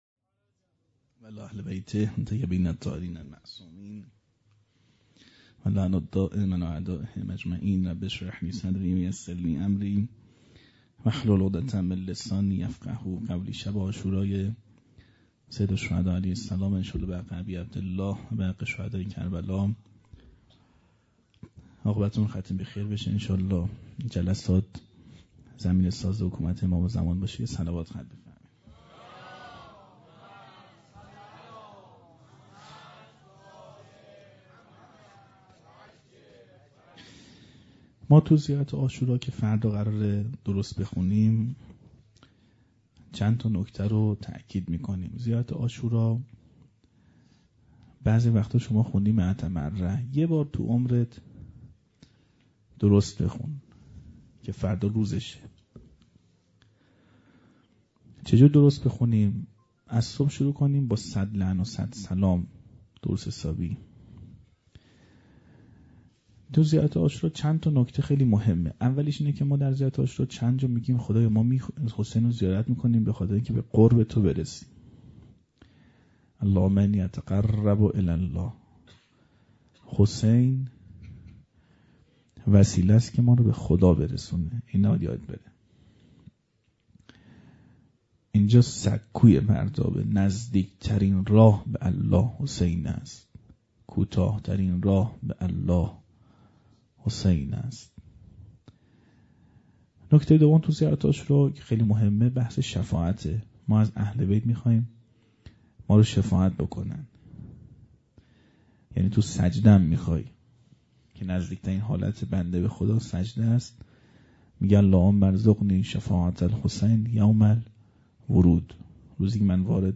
سخنرانی.mp3